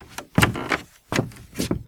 MISC Wood, Foot Scrape 04.wav